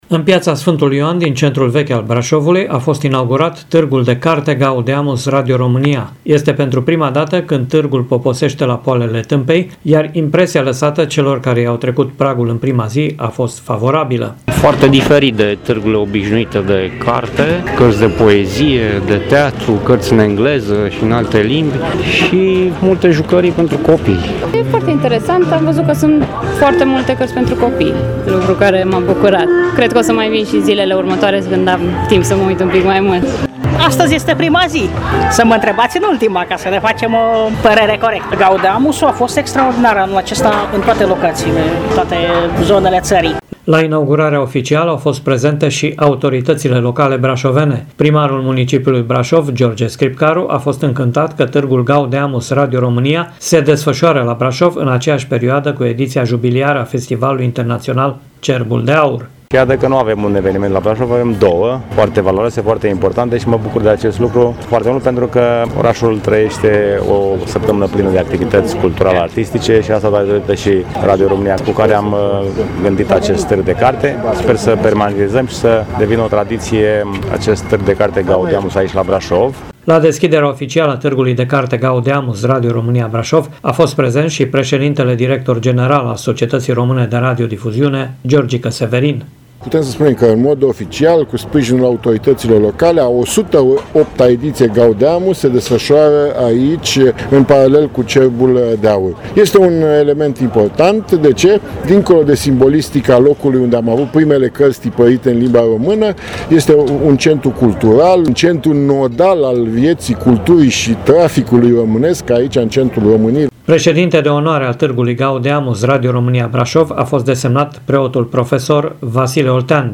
Caravana Gaudeamus a poposit, pentru prima dată la poalele Tâmpei, fiind sprijinită de autoritățile brașovene, la deschiderea oficială fiind prezent și primarul Brașovului, George Scripcaru: